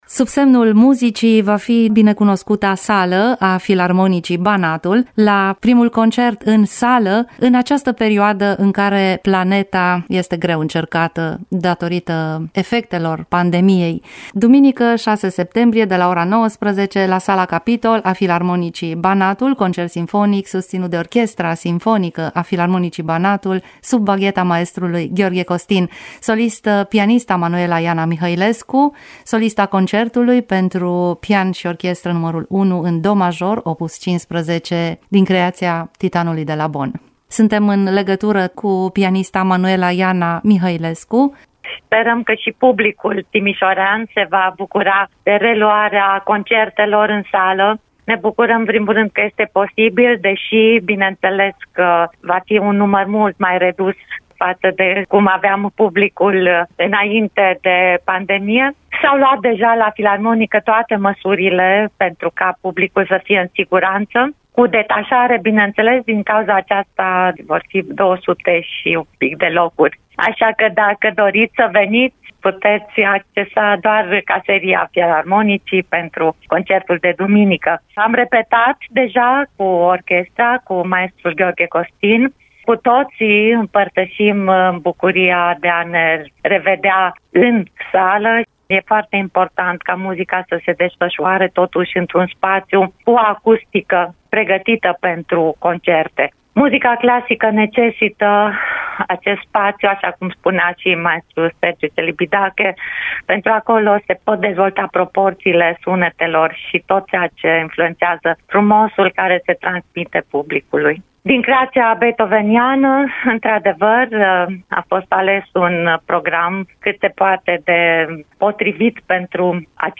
Despre bucuria reîntoarcerii în sala de concert şi opusul beethovenian, un dialog